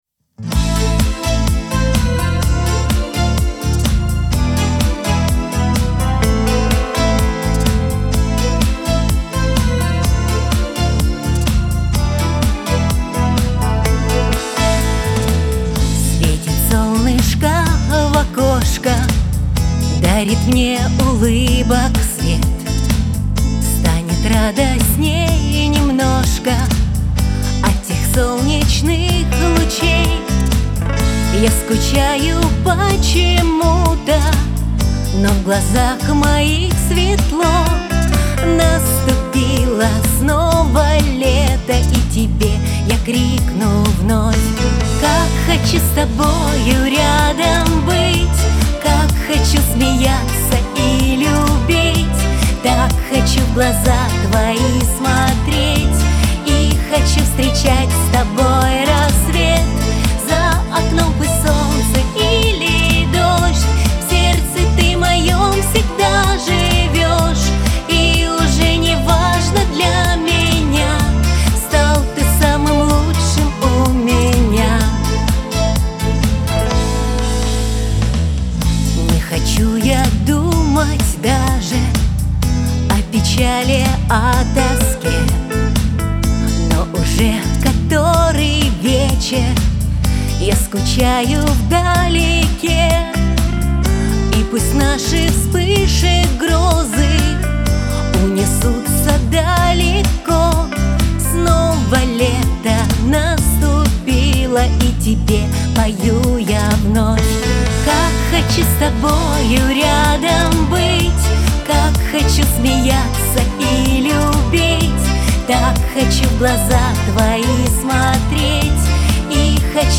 pop
Лирика